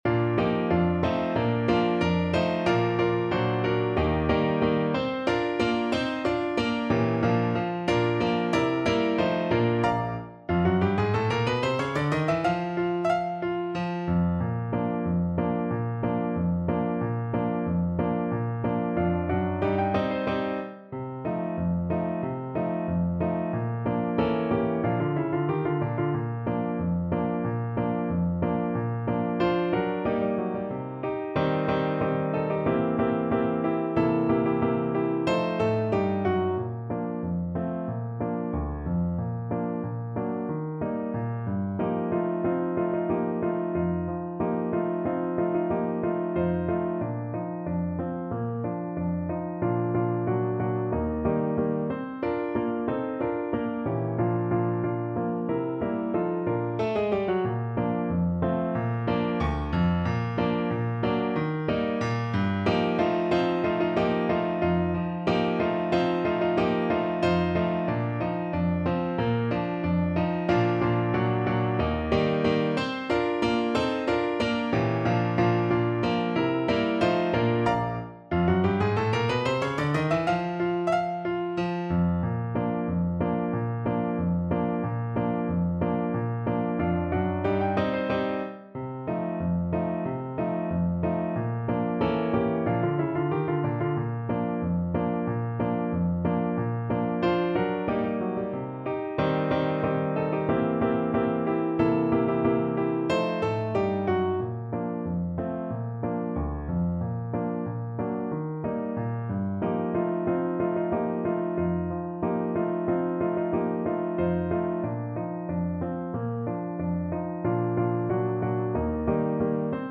2/4 (View more 2/4 Music)
Allegretto =92